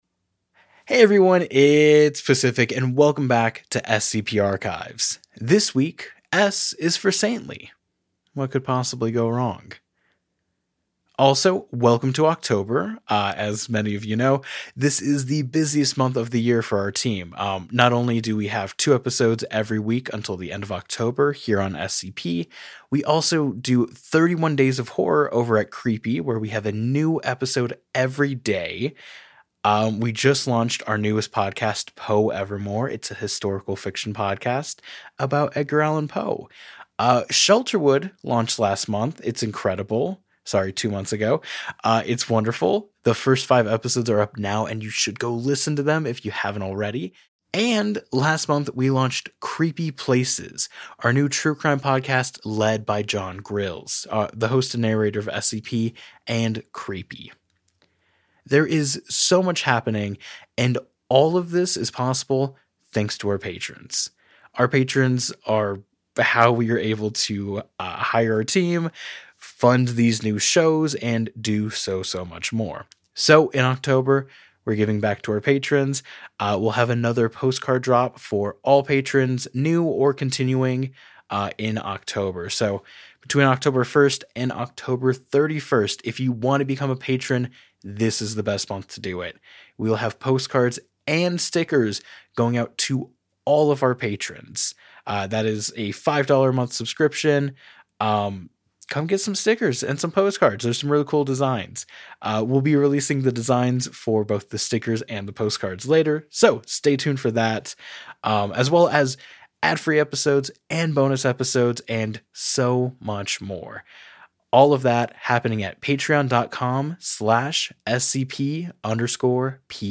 Arts, Society & Culture, Tv & Film, Drama, Fiction, Science Fiction